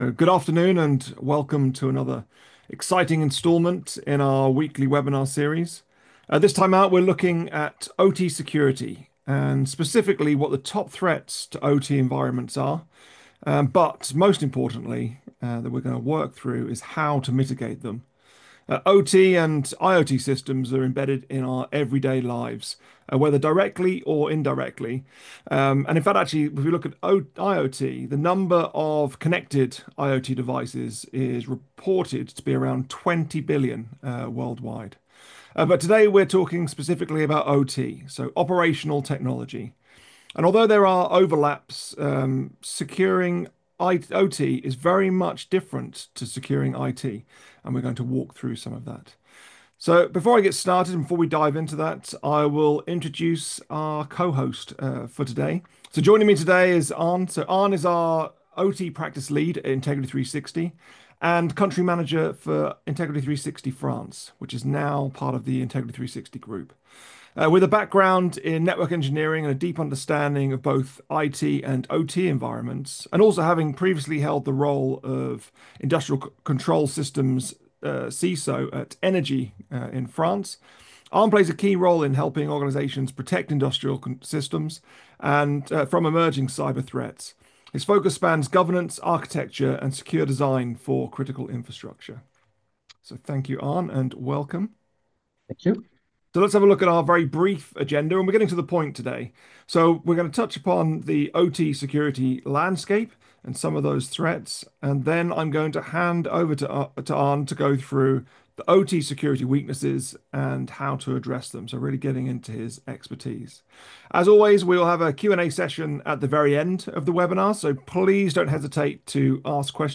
Integrity360 | Webinar | Top OT Security Weaknesses & How to Reduce Risk | on demand